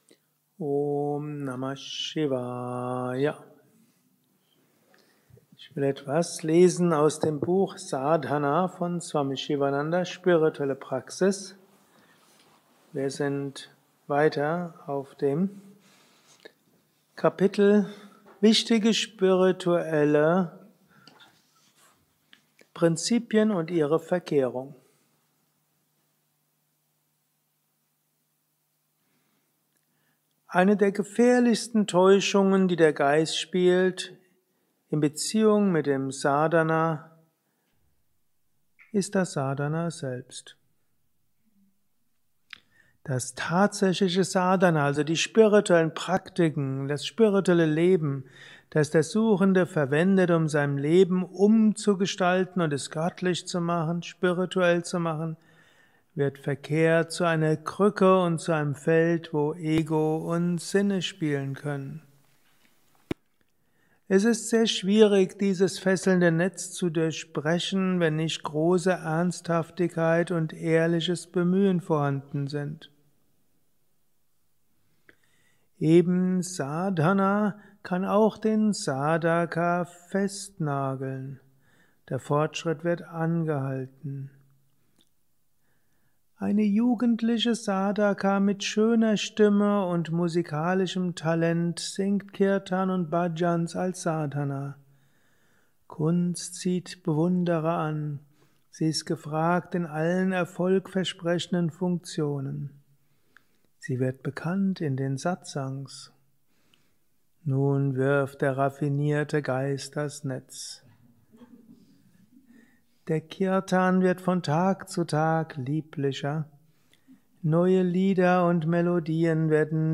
Kurzvorträge
eine Aufnahme während eines Satsangs gehalten nach einer Meditation